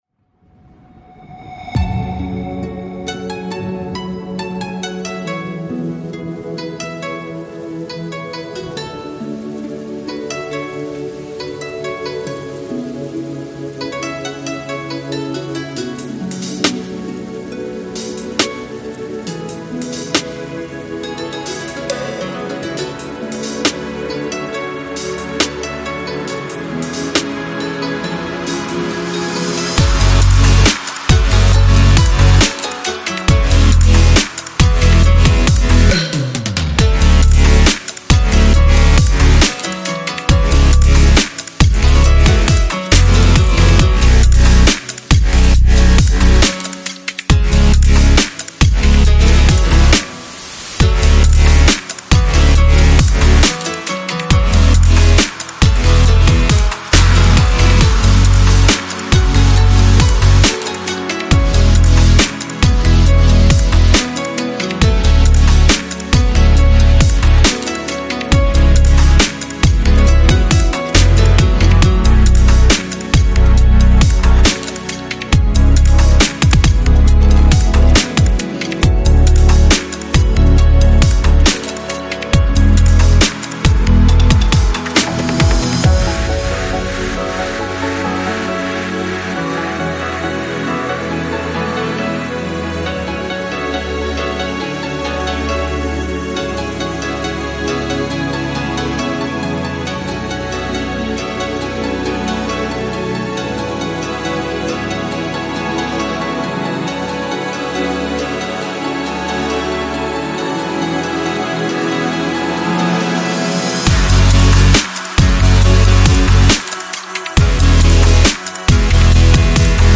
Звучание выделяется яркими битами и запоминающимся припевом